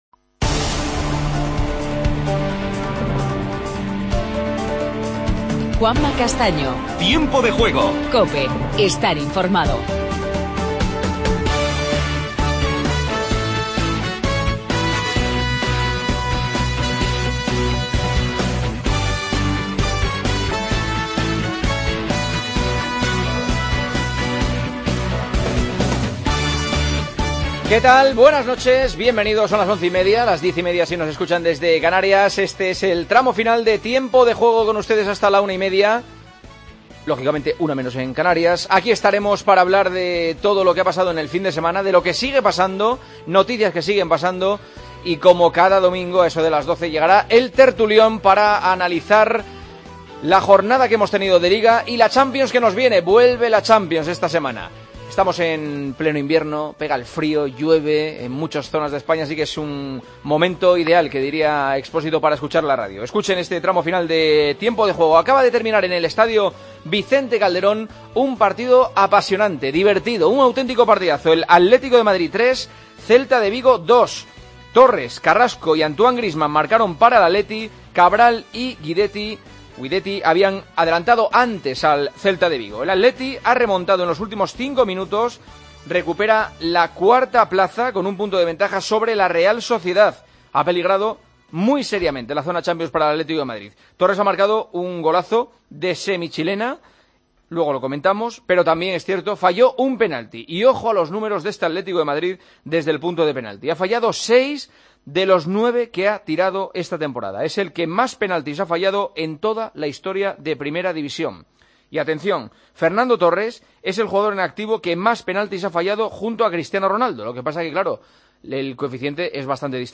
El Atleti gana 3-2 en un partido agónico ante el Celta. Entrevistas a Carrasco y Iago Aspas; escuchamos a Simeone y...